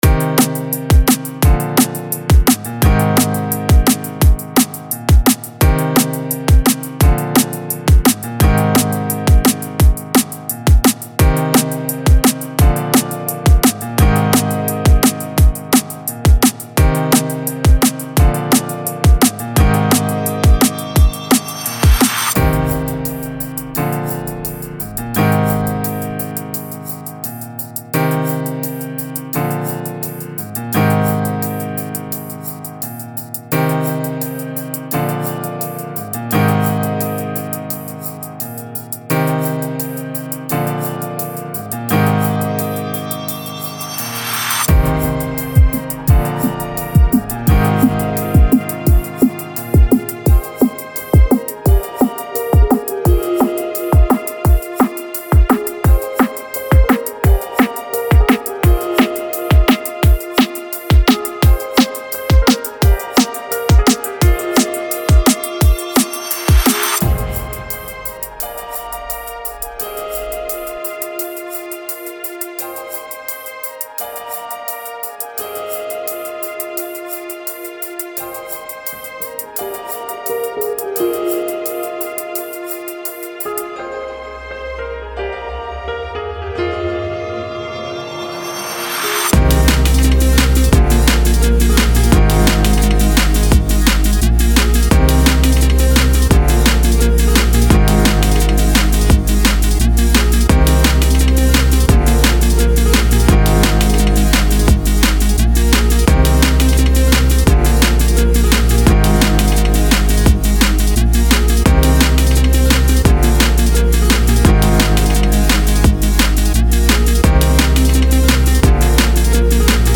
Here is the backing track that I sent off to the vocalist.